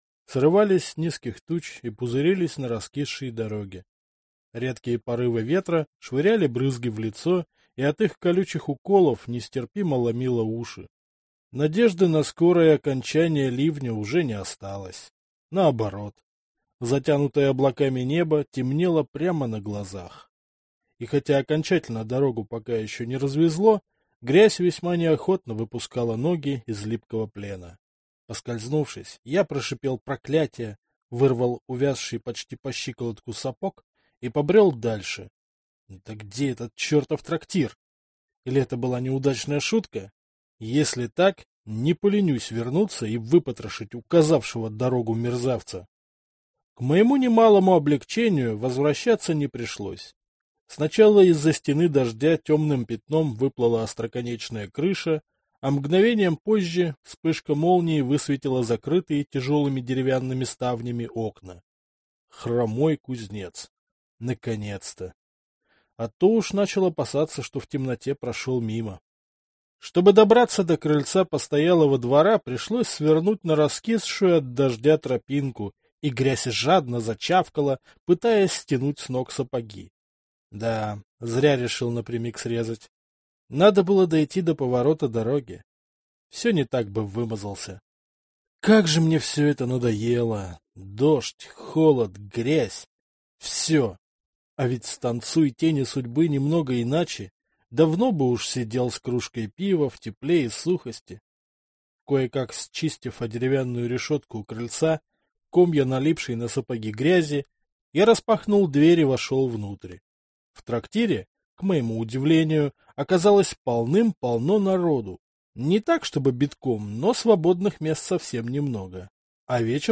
Аудиокнига Повязанный кровью | Библиотека аудиокниг